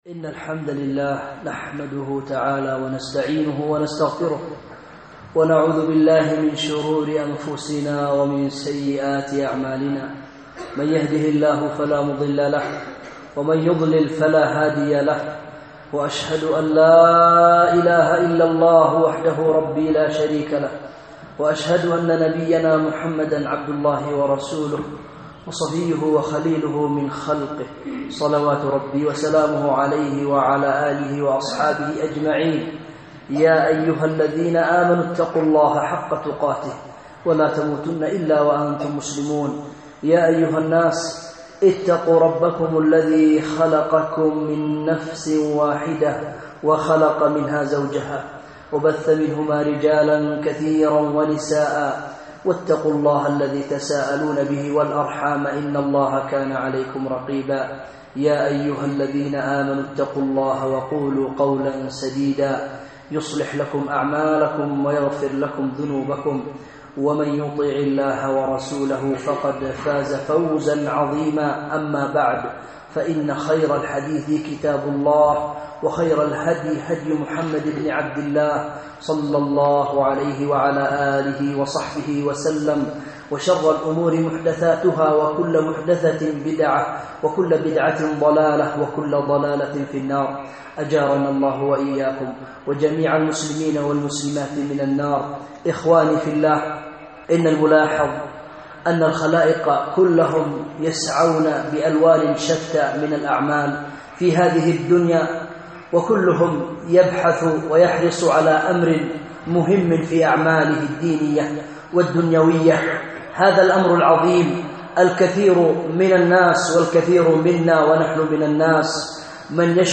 “البركة” خطبة جمعة
أُلقيت بمسجد معاذ بن جبل – شميلة _ بالعاصمة صنعاء